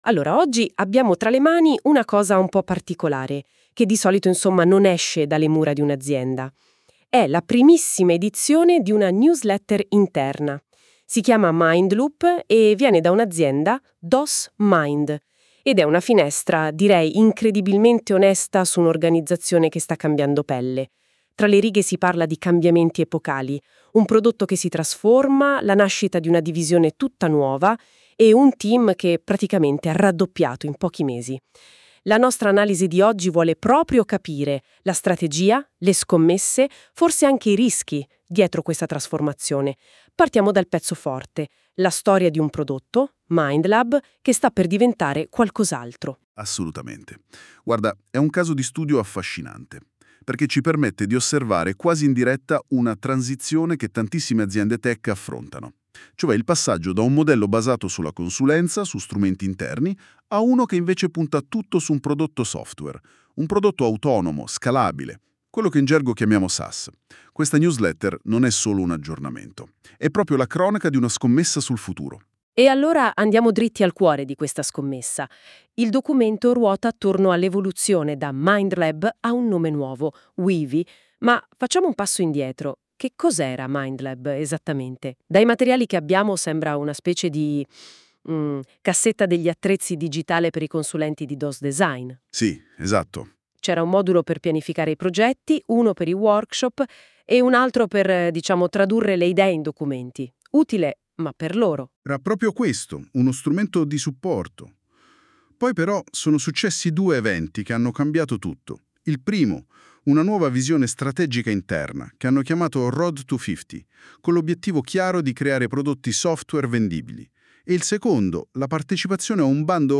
Abbiamo utilizzato l'AI per dare voce ai nostri contenuti, creando un podcast pensato per accompagnarti mentre lavori o sei in viaggio.
Nota bene: essendo una narrazione generata dall'Intelligenza Artificiale, alcune interpretazioni potrebbero variare rispetto all'intento originale.